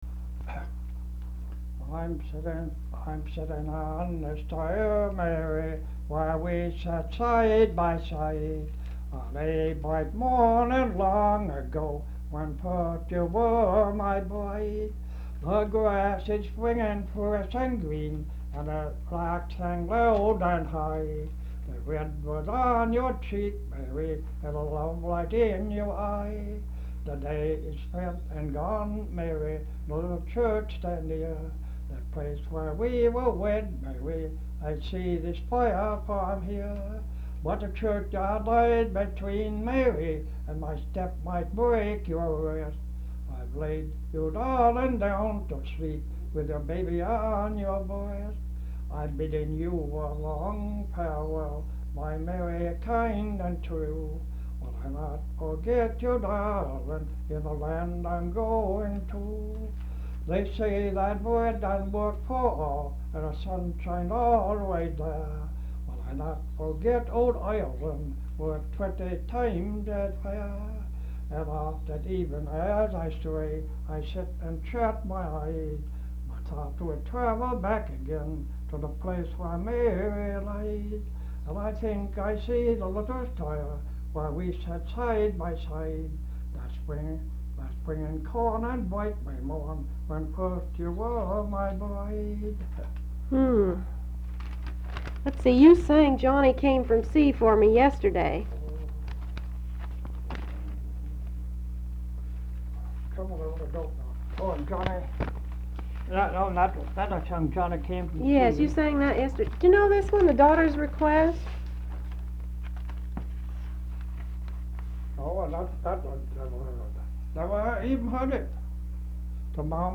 folksongs
Folk songs, English--Vermont
sound tape reel (analog)